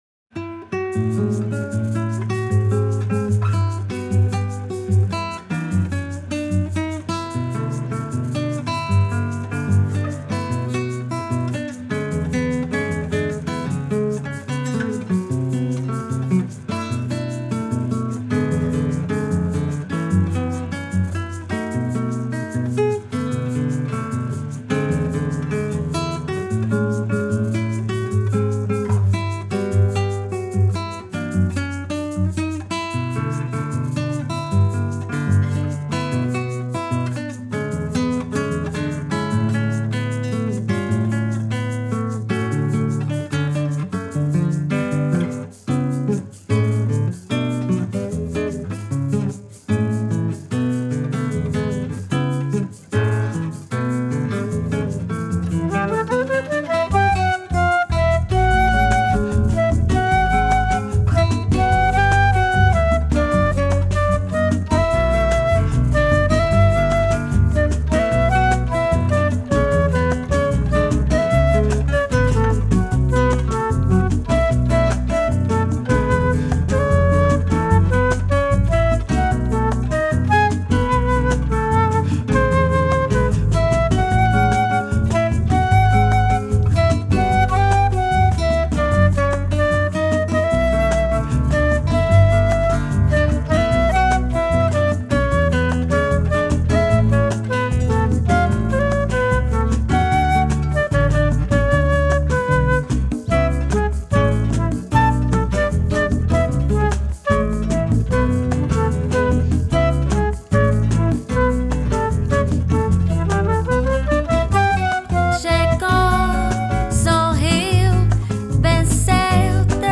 mixed and Mastered in Vancouver, Canada.